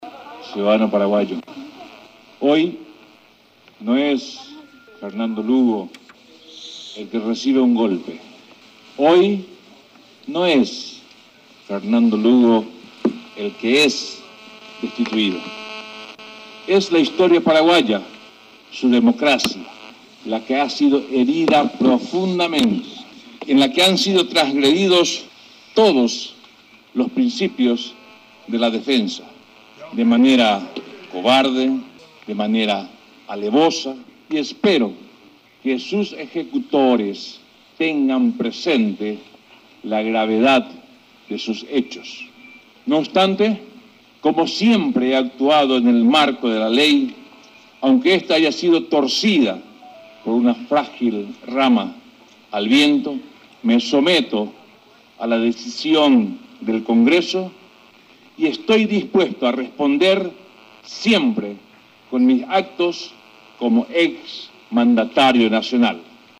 Fragmento del discurso de despedida del expresidente Fernando Lugo